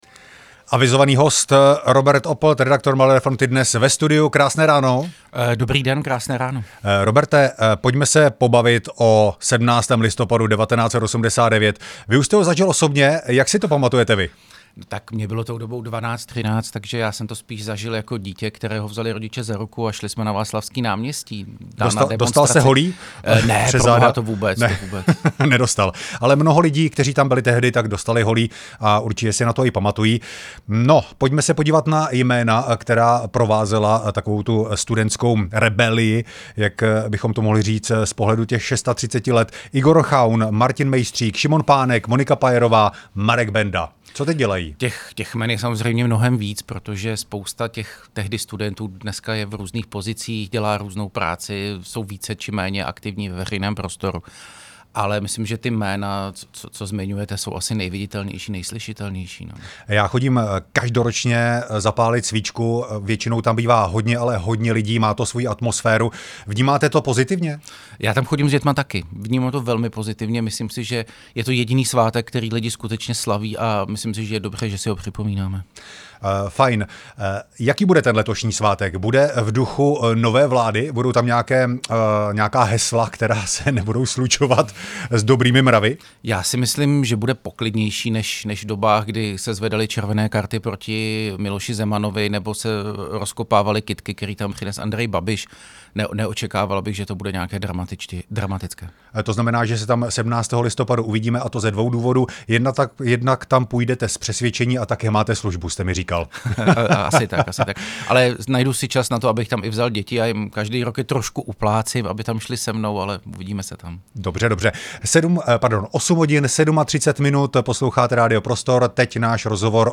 Rozhovory
V rozhovoru se společně ohlížejí za atmosférou tehdejších dní i za osobními vzpomínkami na začátek sametové revoluce.